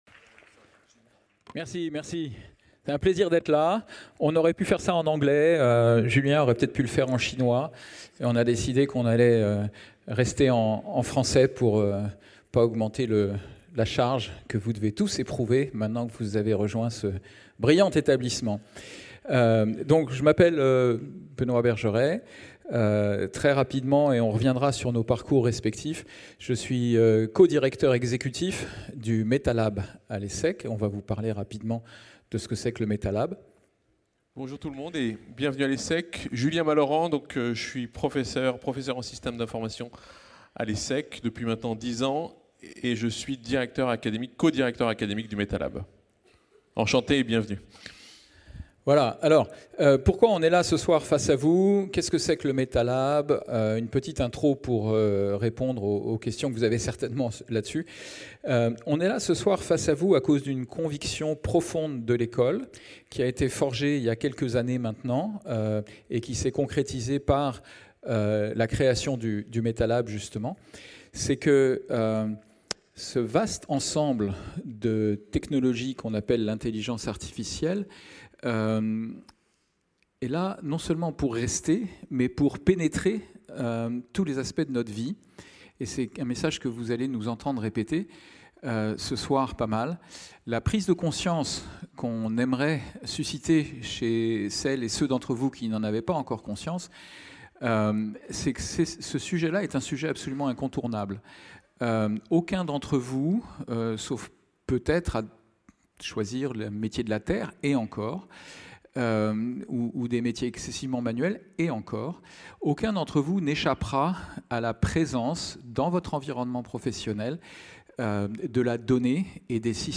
Table ronde IA & Data